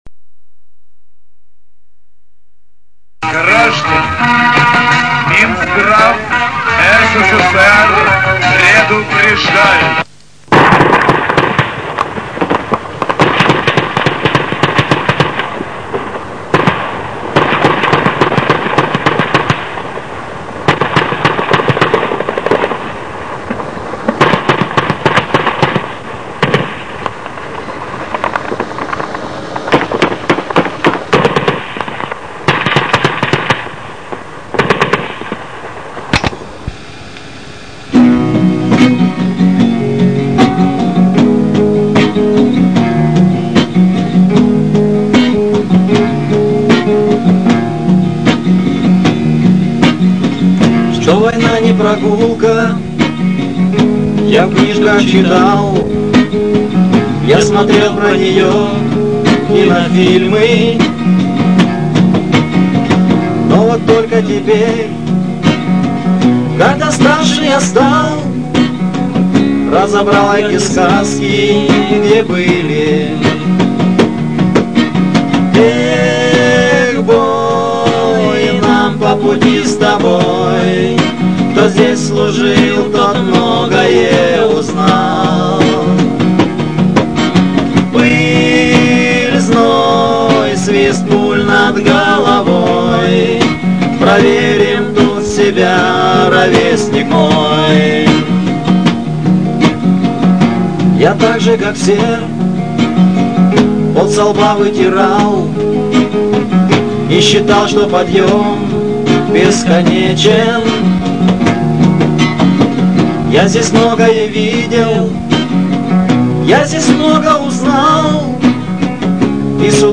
Главная » Файлы » Песни под гитару » Песни у костра
Песни у костра [44]